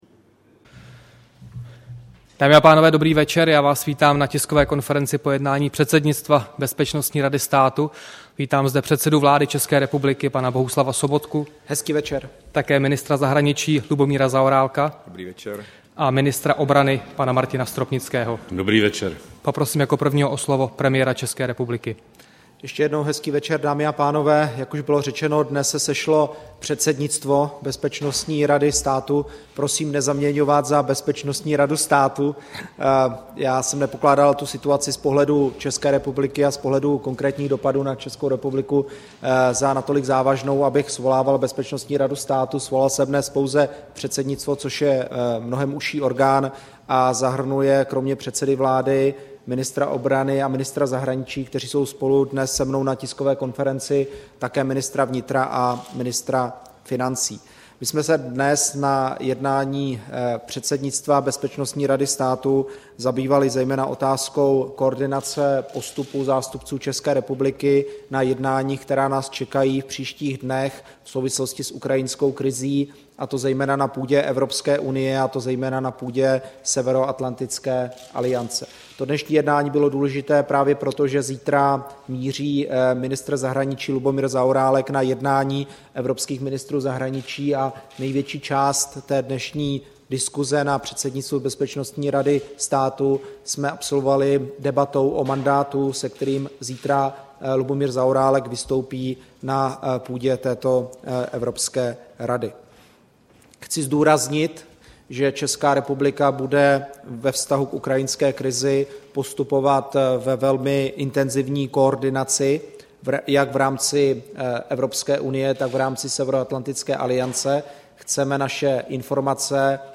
Tisková konference po mimořádném jednání předsednictva Bezpečnostní rady státu, 2. března 2014